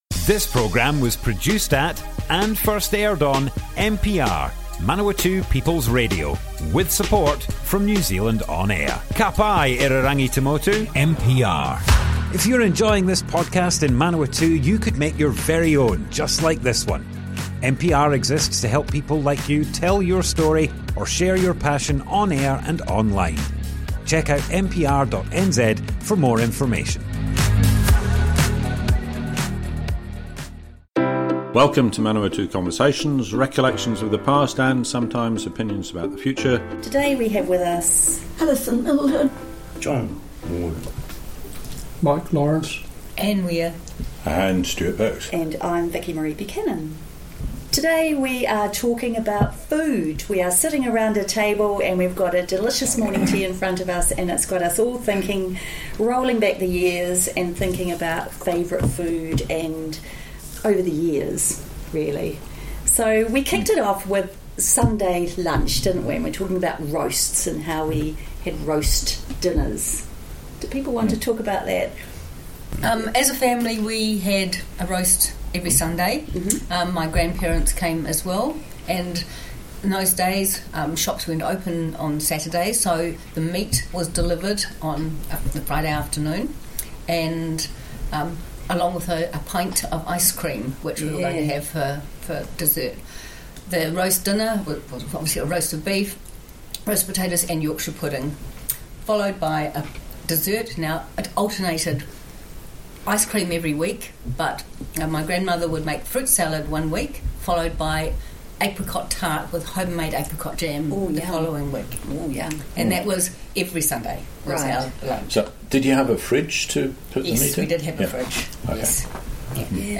Group discussion, part 1, food reminiscencies - Manawatu Conversations